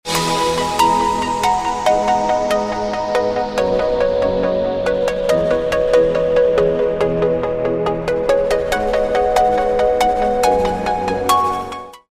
رینگتون پرتحرک و زیبای
(برداشتی آزاد از موسیقی های بی کلام خارجی)
ertefaa_ringtone_241275.mp3